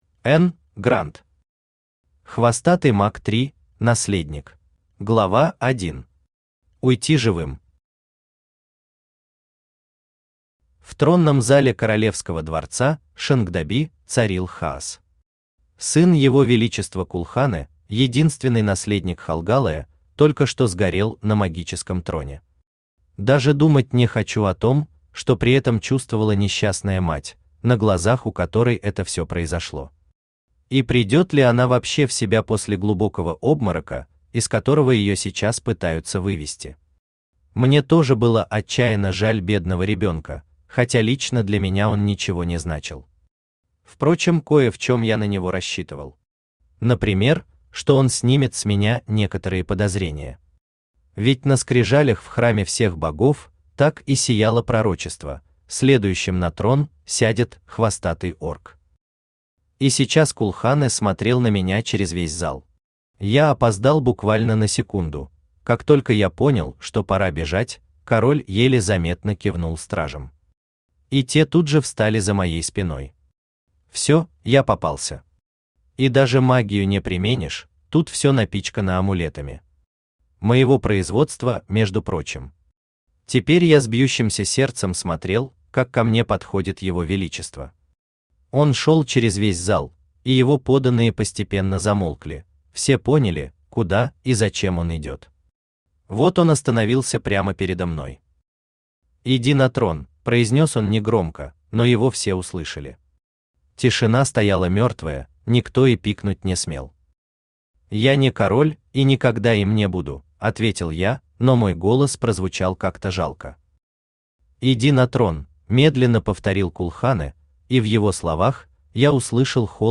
Аудиокнига Хвостатый маг 3: наследник | Библиотека аудиокниг
Aудиокнига Хвостатый маг 3: наследник Автор Н. Гранд Читает аудиокнигу Авточтец ЛитРес.